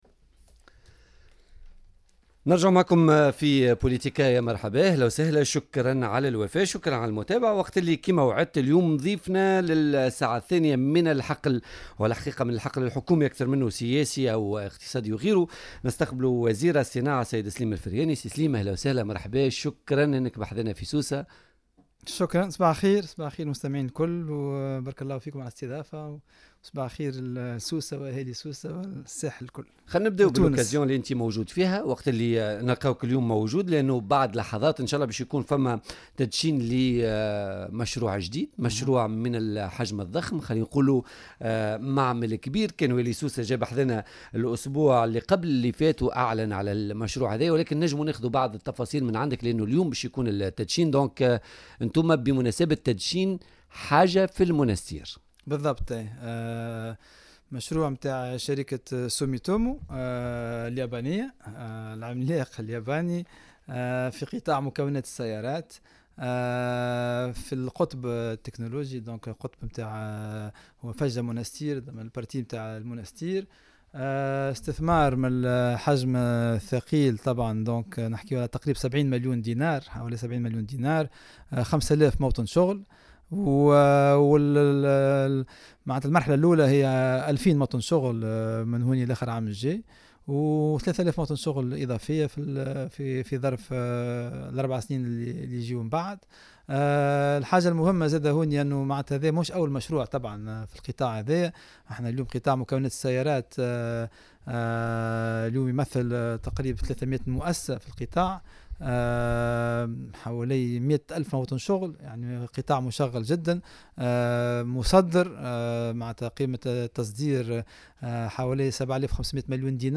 أكد وزير الصناعةوالمؤسسات الصغرى والمتوسطةسليم الفرياني ضيف بولتيكا اليوم الخميس 5 ديسمبر 2019 أنه سيتم اليوم تدشين فرع شركة يابانية مختصة في مكونات السيارات بالقطب التكنولوجي بالمنستير .